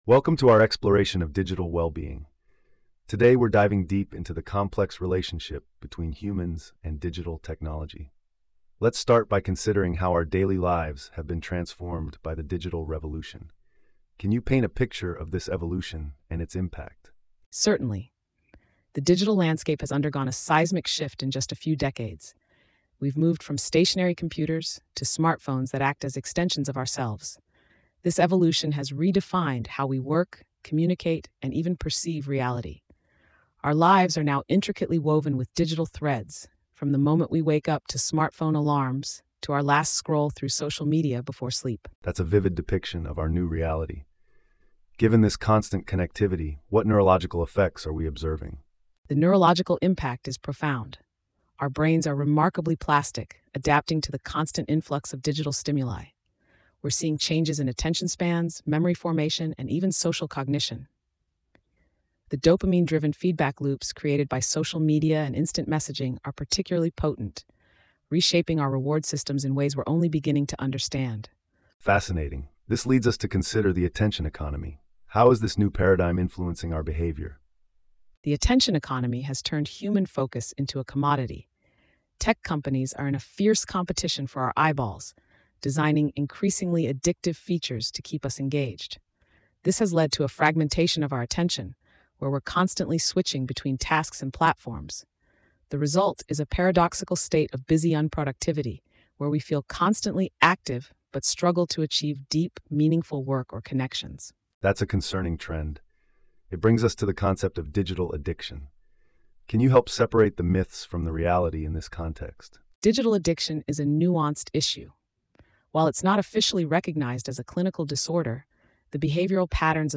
PodWaveAI - AI-Powered Podcast Generator